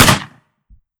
12ga Pump Shotgun - Gunshot B 003.wav